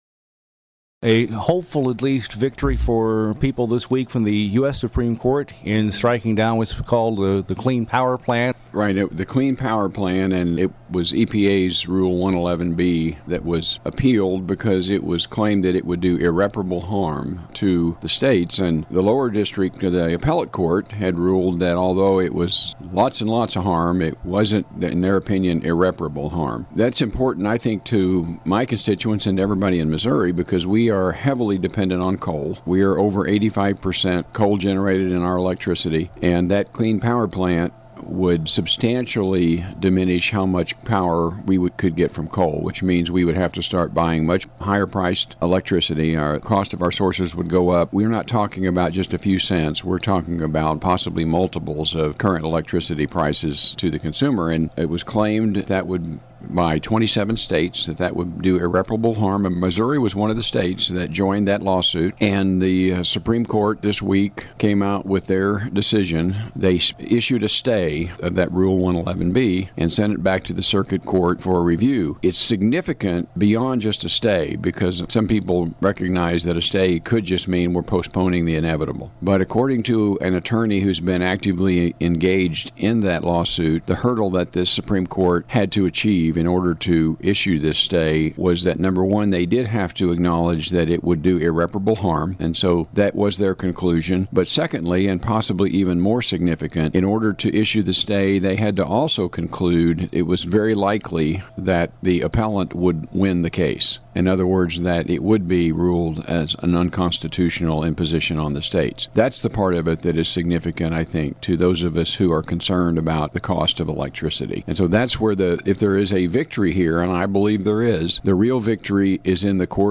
The new audio link includes Sen. Emery discussing the U.S. Supreme Court’s decision on EPA regulations against coal.